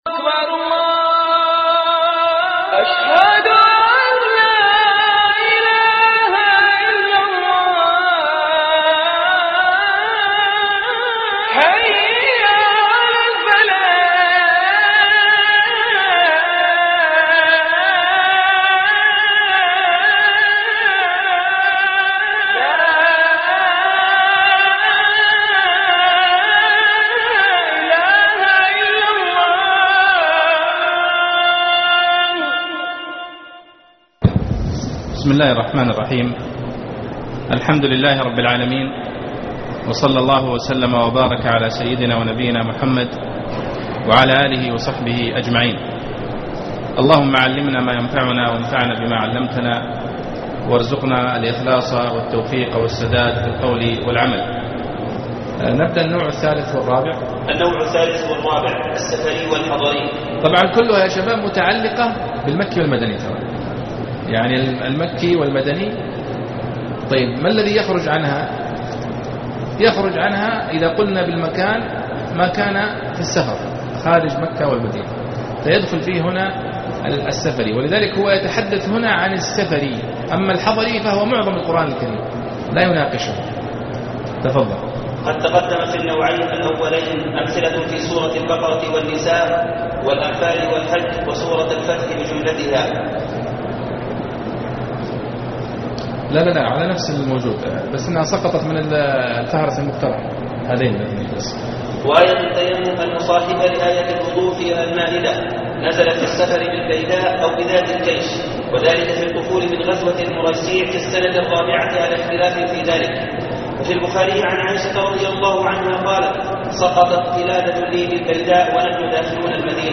أنواع علوم القرآن ( 27/3/2014)دروس من الحرم